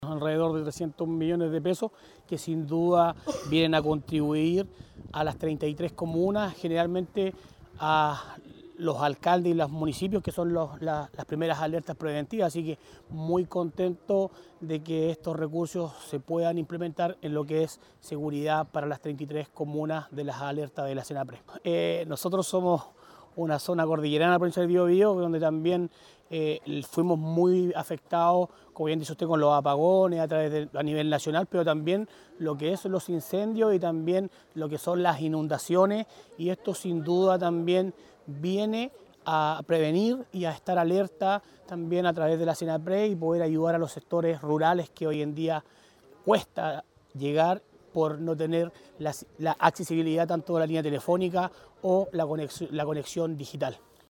Quien también celebró la iniciativa fue el consejero regional de la provincia de Biobío, Roberts Córdova, quien indicó que la zona cordillerana fue afectada “por apagones, incendios e inundaciones.